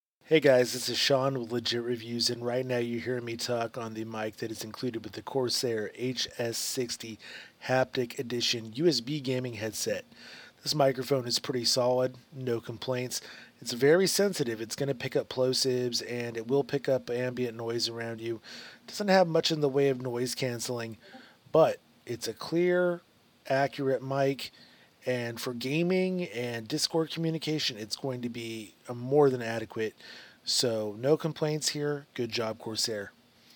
It is a bit sensitive and will pick up ambient noise, but it is very clear and accurate. I have included an MP3 sample of the microphone in action so that you can get an idea of what kind of quality to expect in typical scenarios. I did have an air conditioner going in the background during recording.
The Corsair HS60 Haptic USB Gaming Headset offers a decent microphone
corsairhs60mictest.mp3